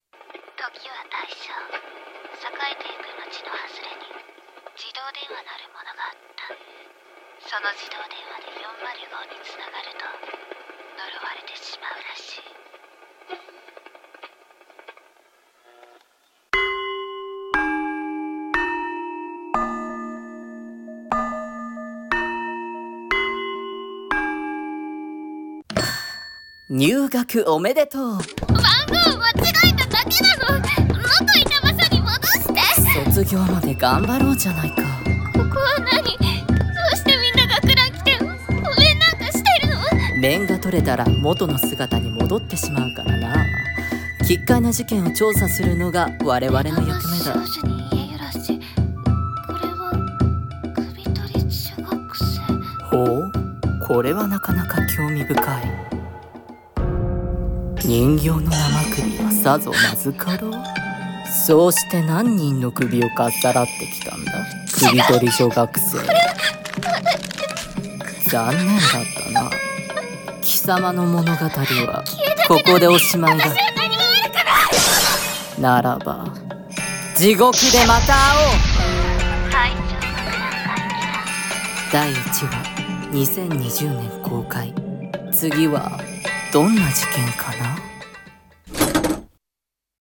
予告風声劇台本 『大正学ラン怪奇譚』